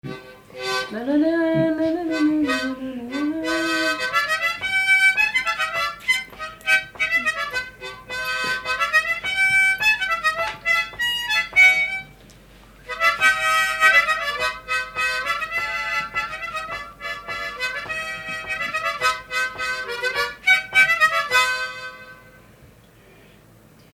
Sixt-Fer-à-Cheval
Pièce musicale inédite